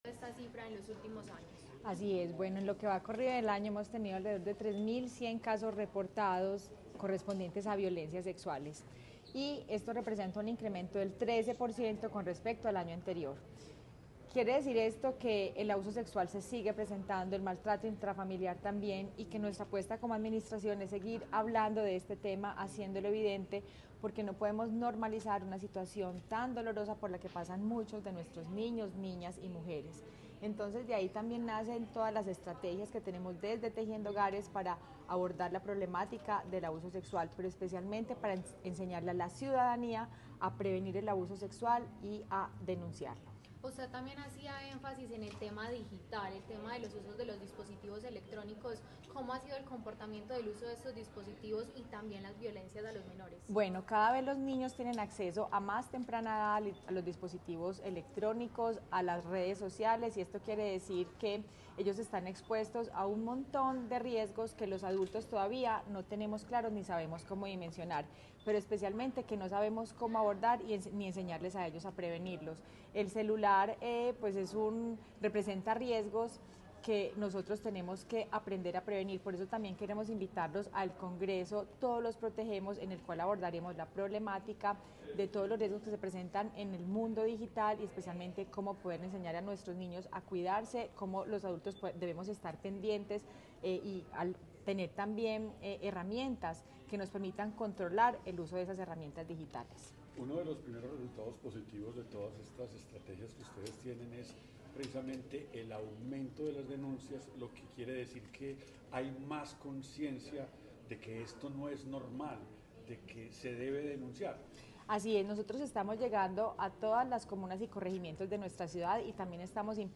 Declaraciones de la primera dama, Margarita María Gómez Marín
Declaraciones-de-la-primera-dama-Margarita-Maria-Gomez-Marin.mp3